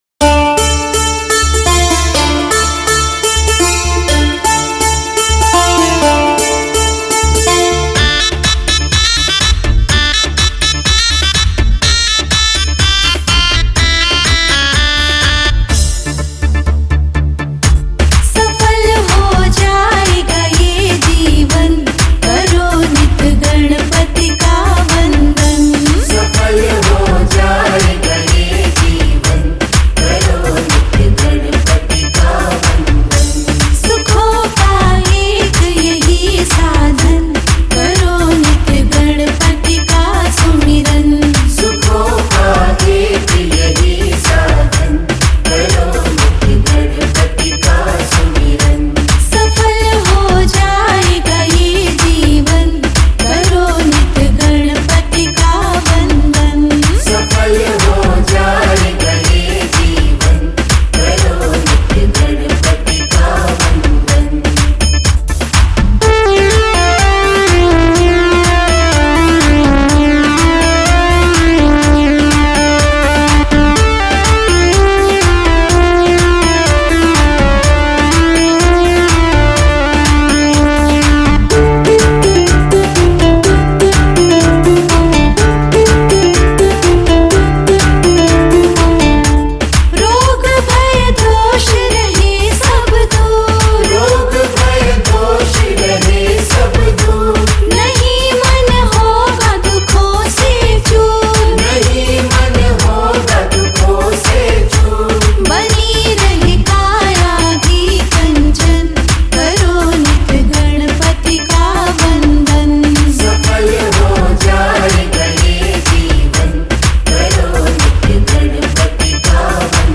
Ganesh Bhajan
Hindi Bhajan